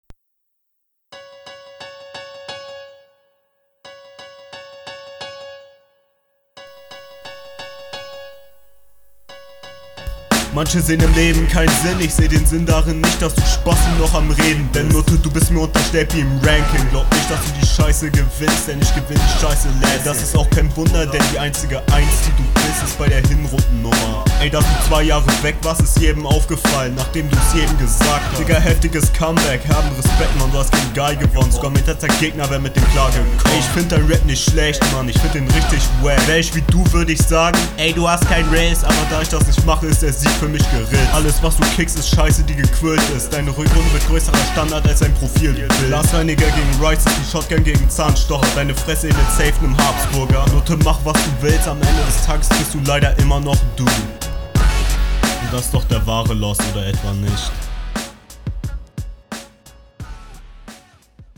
krankes pipen im hintergrund, tinitus simulator sonst runde ganz okay, doubles nicht to auf den …
Flow leider wieder oft offbeat.